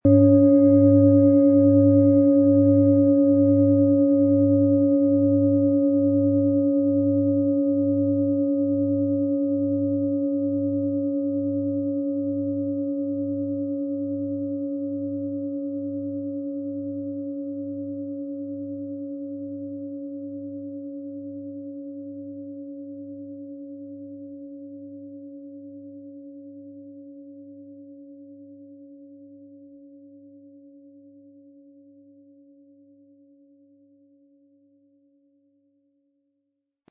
Planetenklangschale Pluto, von Hand gefertigt.
• Mittlerer Ton: Uranus
Unter dem Artikel-Bild finden Sie den Original-Klang dieser Schale im Audio-Player - Jetzt reinhören.
PlanetentönePluto & Uranus
MaterialBronze